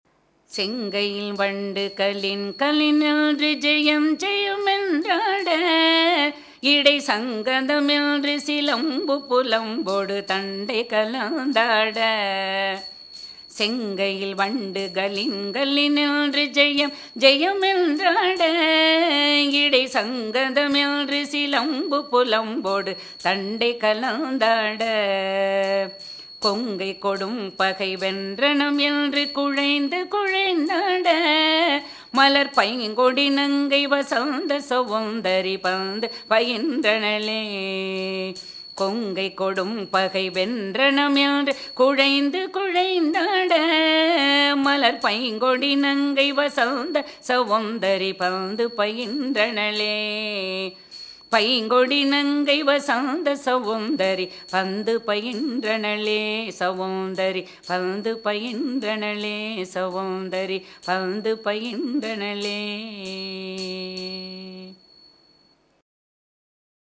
இதற்குத் தகுந்த நிலையில் பாடலமைதியும், இசையமைதியும் தாள அமைதியோடு அமைக்கப்படும்.
அப்பொழுது அவளுடைய செங்கையில் உள்ள வளையல்கள் கலீர் கலீர் என்றும் செயம் செயம் என்றும் ஒலி முழங்கின. இவ்வகை ஒலிநயம் மிக்கப் பாடல்கள் ஆடலை அழகுபடுத்தும்.